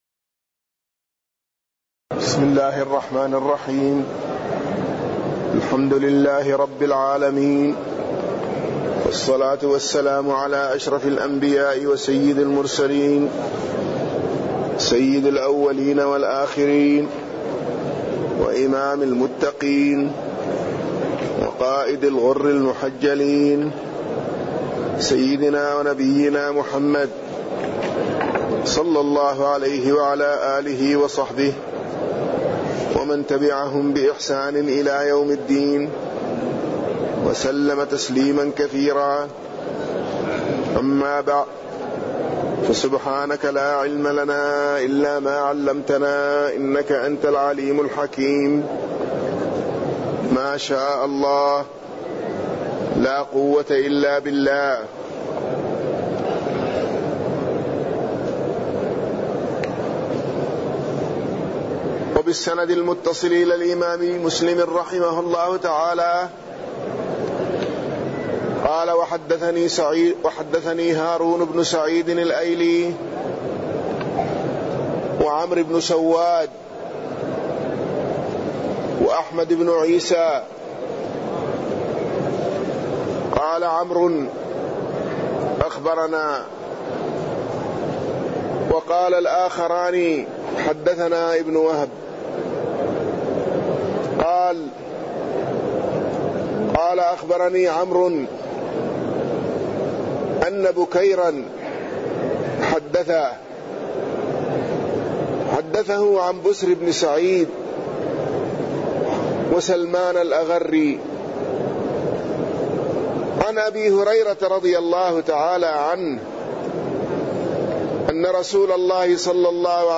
تاريخ النشر ١١ ذو القعدة ١٤٢٩ هـ المكان: المسجد النبوي الشيخ